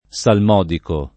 salmodico [ S alm 0 diko ]